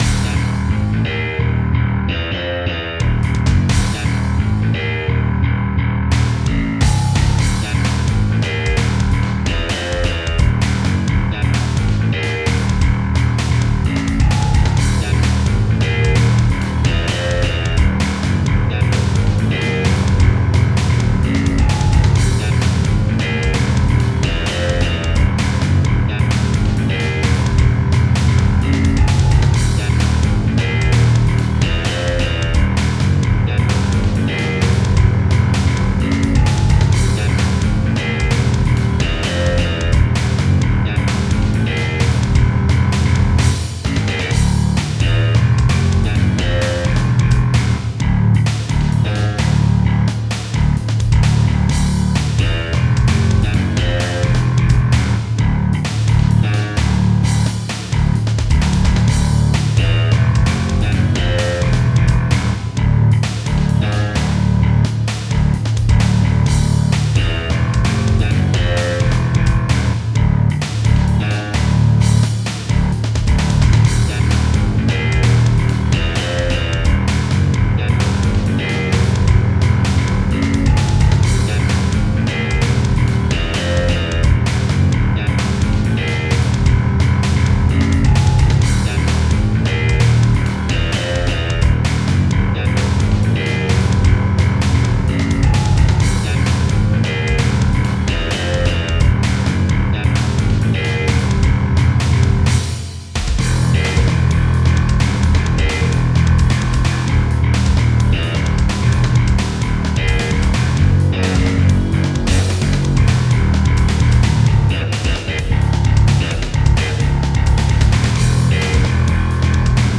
A Bass Guitar and ... well thats basically it